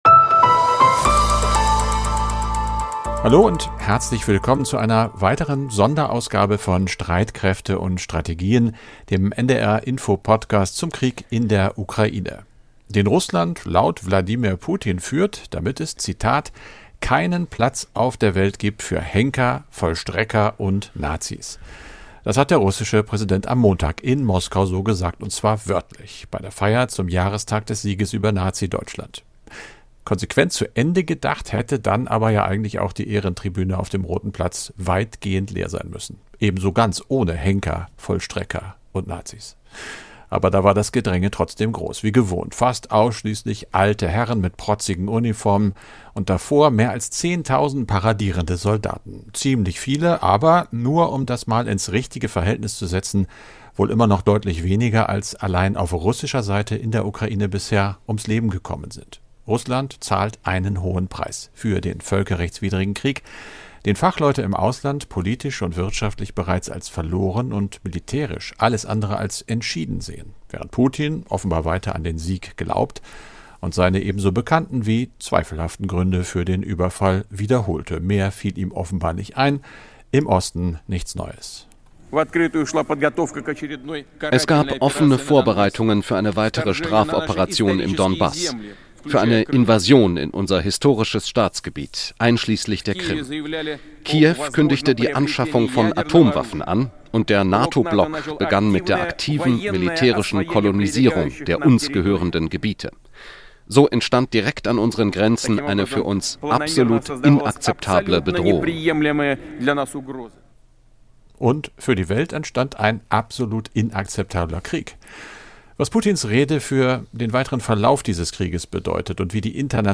Nachrichten - 28.06.2022